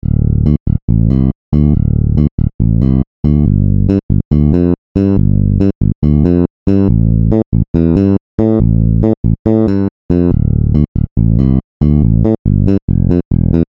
Вложения Demo_Bass.mp3 Demo_Bass.mp3 430,5 KB · Просмотры: 290